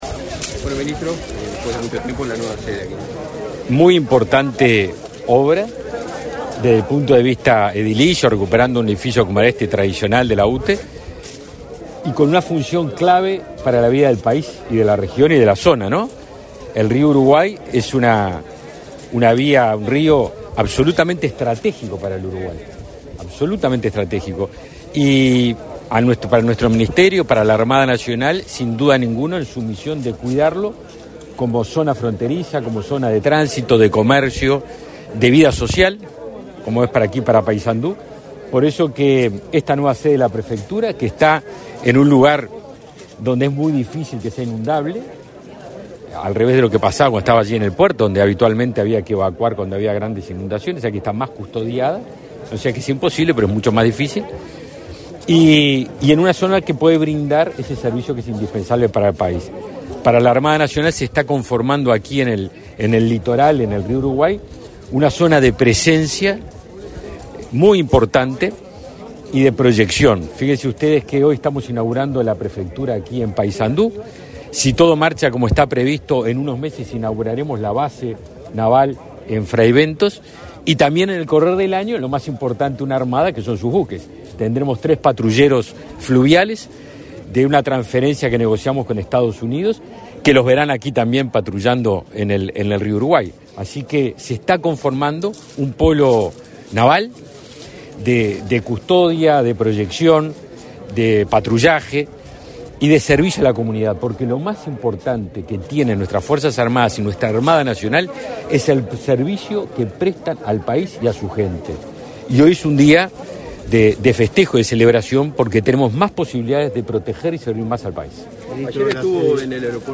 Declaraciones a la prensa del ministro de Defensa Nacional, Javier García
Declaraciones a la prensa del ministro de Defensa Nacional, Javier García 27/01/2022 Compartir Facebook X Copiar enlace WhatsApp LinkedIn El titular de Defensa, Javier García, participó en la inauguración de la sede de la Prefectura en Paysandú este 27 de enero. Luego del evento, efectuó declaraciones a la prensa.